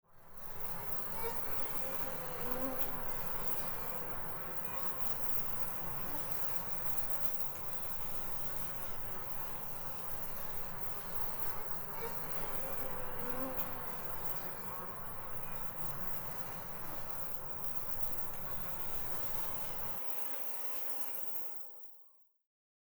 На этой странице собраны разнообразные звуки мошек: от тихого жужжания до назойливого писка.
Шум мошек в поле летом